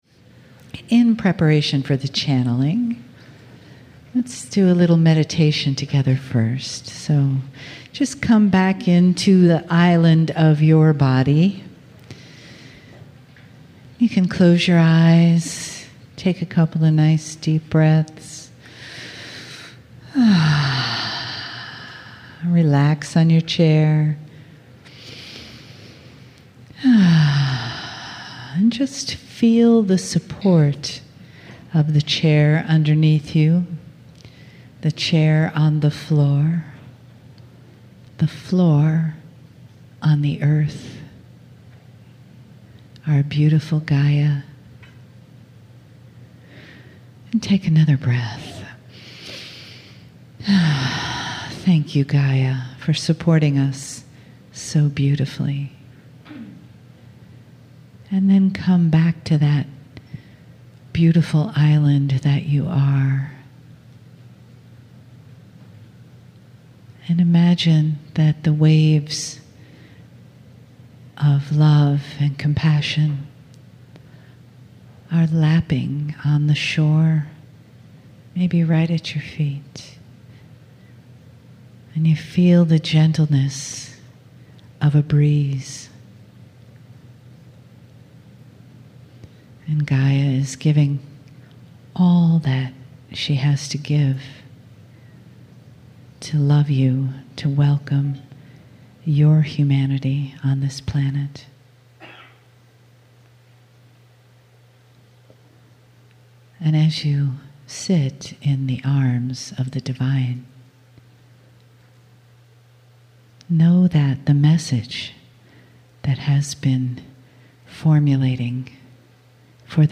Back Gaithersburg, Maryland Saturday
2013 "The Mysterious Innate" Live Channelling